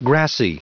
Prononciation du mot grassy en anglais (fichier audio)
Prononciation du mot : grassy
grassy.wav